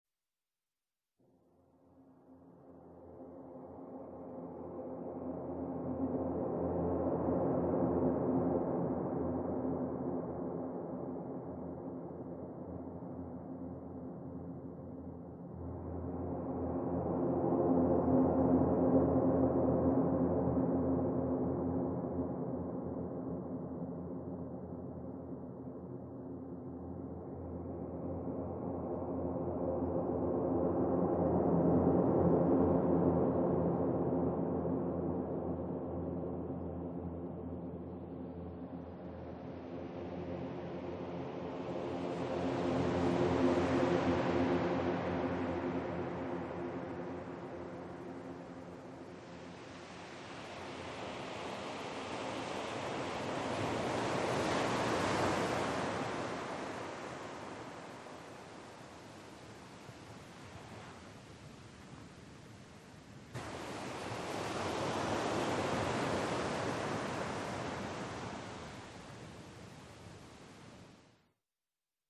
7.6 scene 7 wave sequence 2.MP3